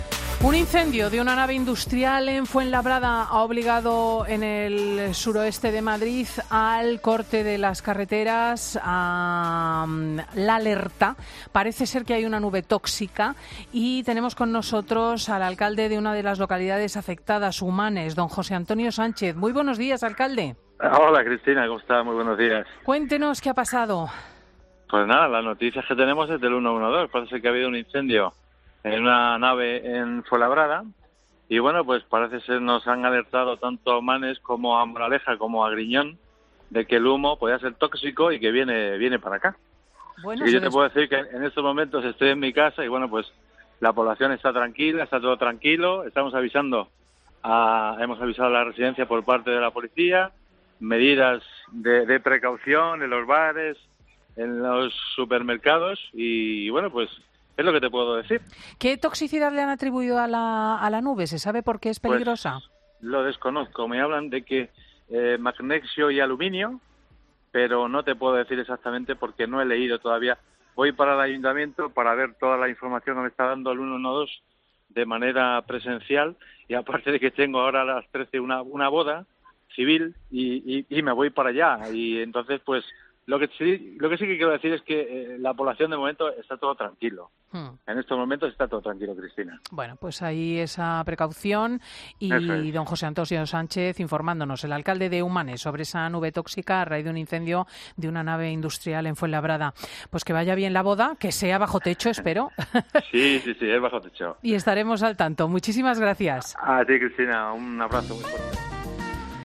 José Antonio Sánchez, alcalde de Humanes, explica en 'Fin de semana' el aviso que ha recibido para que no salga de su vivienda ante la nube tóxica que ha originado el incendio de 40 toneladas de virutas de magnesio y aluminio en un polígono industrial de Fuenlabrada.